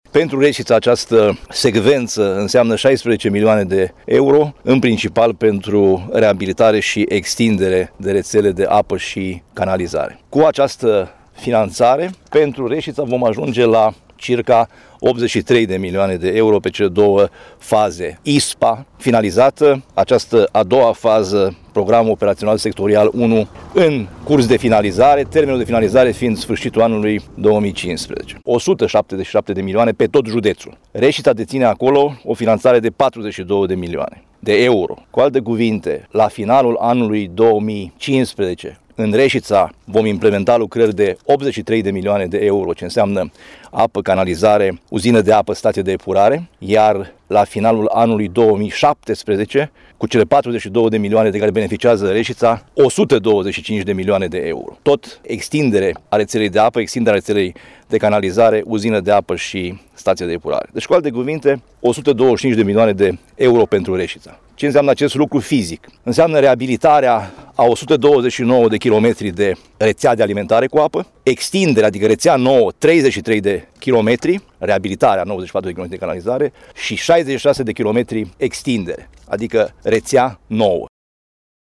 Ascultaţi ce a afirmat preşedintele Consiliului Judeţean Caraş-Severin, Sorin Frunzăverde: